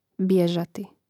bjèžati bježati